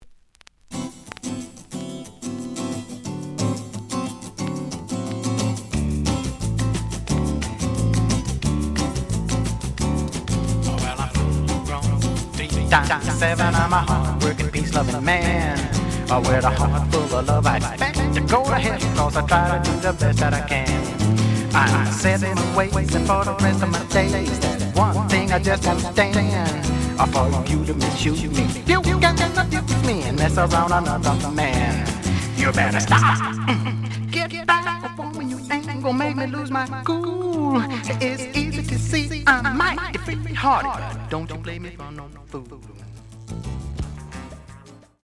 The audio sample is recorded from the actual item.
●Genre: Latin Jazz